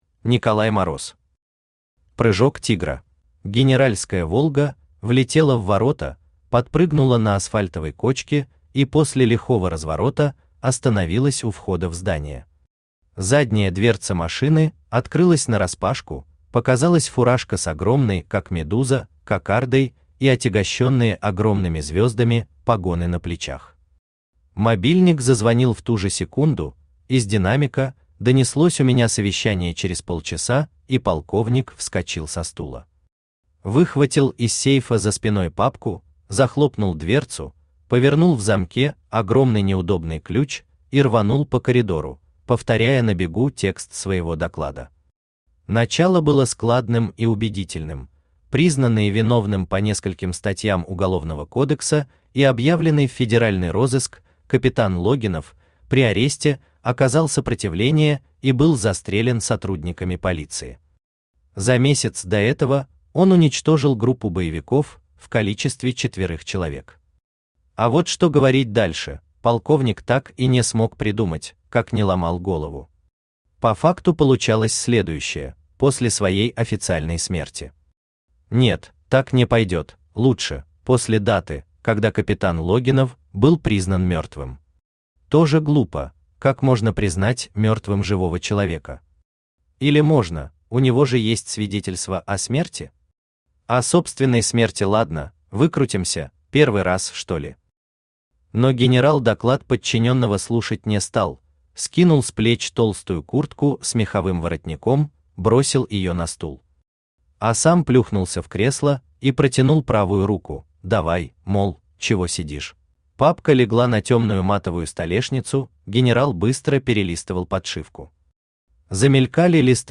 Аудиокнига Прыжок тигра | Библиотека аудиокниг
Aудиокнига Прыжок тигра Автор Николай Мороз Читает аудиокнигу Авточтец ЛитРес.